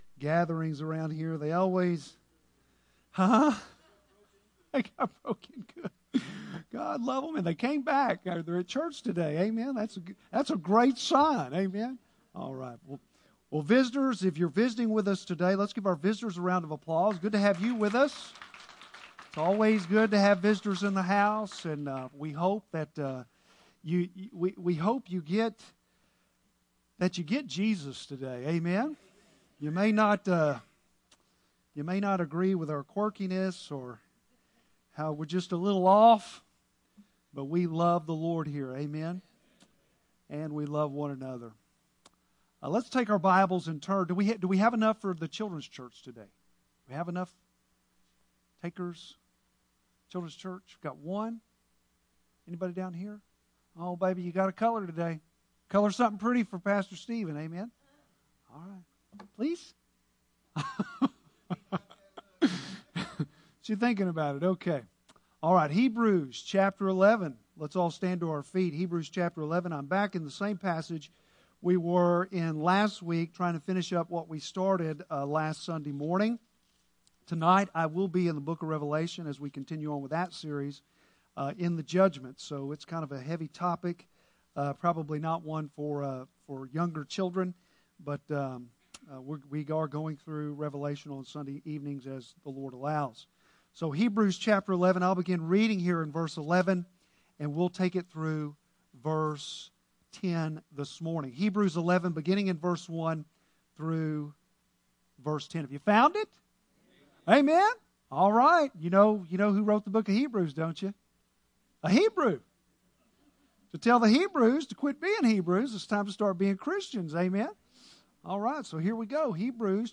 Bible Text: Hebrews 11:1-10 | Preacher